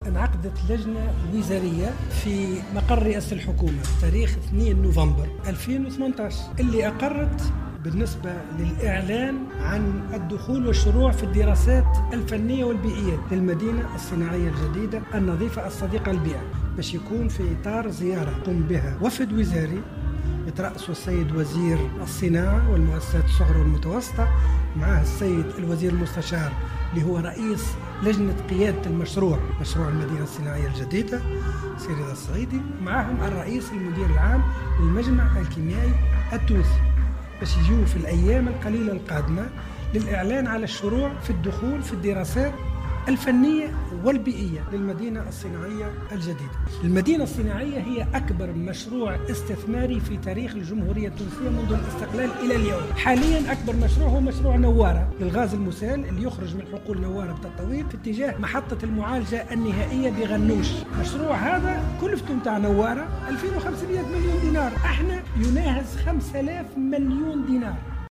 قال والي قابس منجي ثامر في تصريح لمراسل الجوهرة "اف ام" اليوم الجمعة 16 نوفمبر 2018 أنه من المنتظر في الأيام القليلة القادمة الإعلان عن الشروع في انجاز الدراسات الفنية والبيئية لمشروع المدينة الصناعية الجديدة.